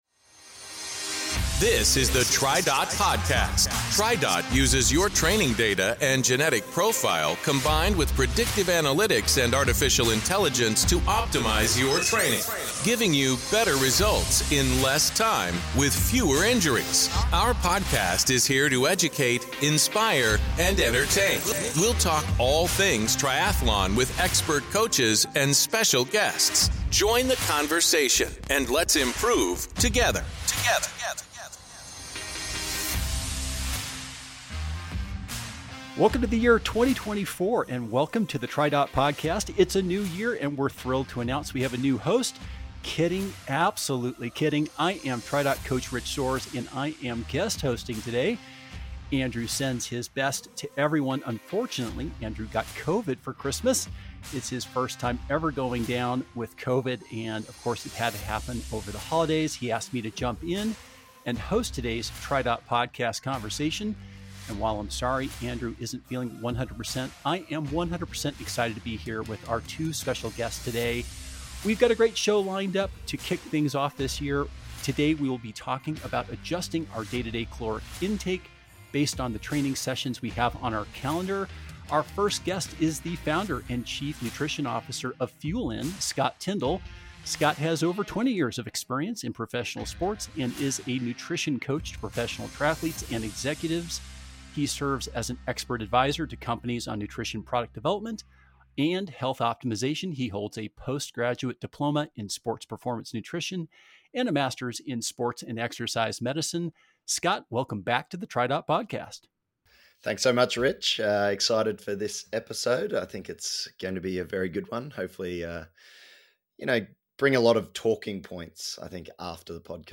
Don't miss this illuminating conversation on the importance of personalized fueling and how it can help you reach your triathlon goals.